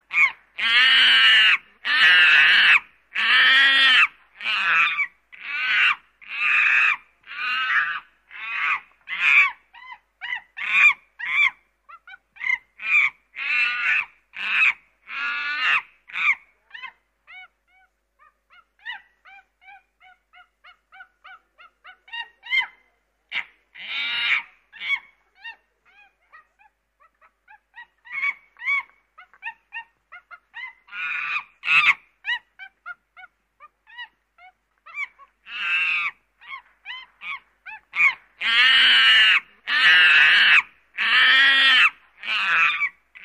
Kategorie Zwierzęta